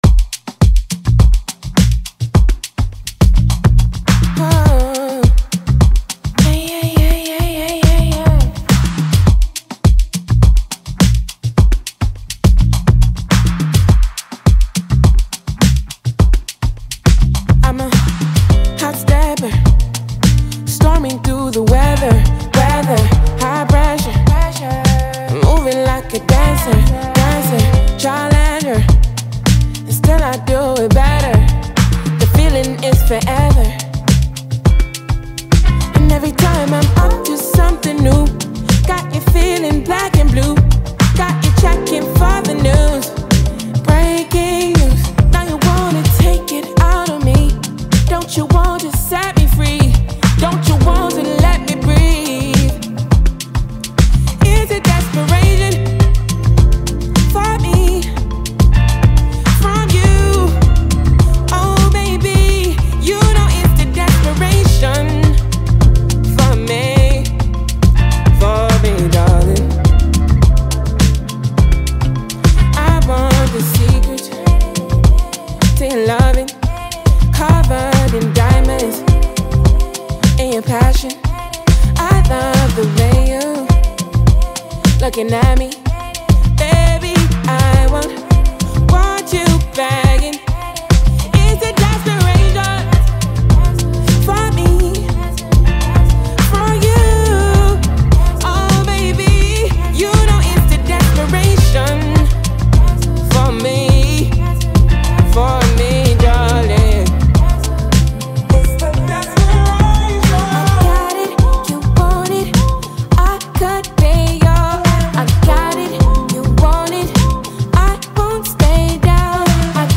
Nigerian talented singer and songwriter